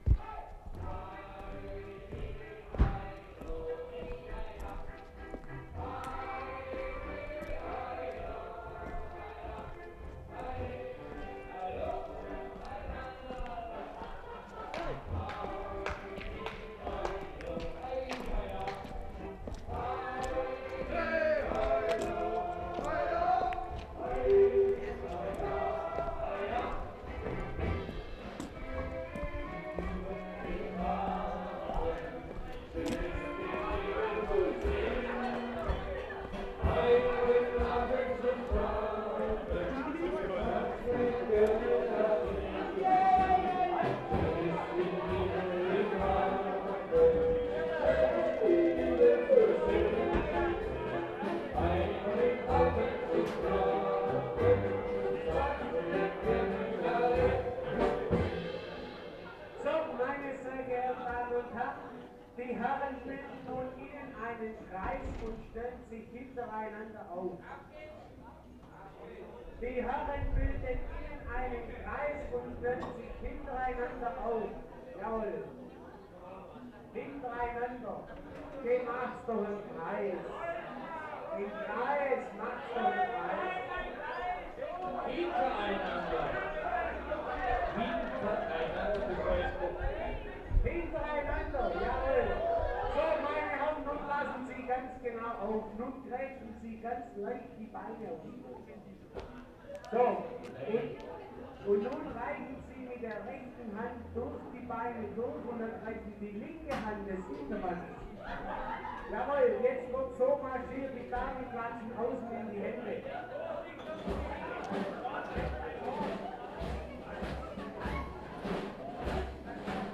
WORLD SOUNDSCAPE PROJECT TAPE LIBRARY
Bissingen, Germany March 1/75
SINGING IN GASTHAUS, with accordion and percussion
2. Walking into the Gasthaus. Entertainment, singing. Announcer is arranging formation of a dance, which demands comical, absurd postures. People responding with laughing and shouting. Recordist back outside.